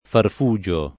vai all'elenco alfabetico delle voci ingrandisci il carattere 100% rimpicciolisci il carattere stampa invia tramite posta elettronica codividi su Facebook farfugio [ farf 2J o ] s. m. (bot.) — latinismo per «farfara»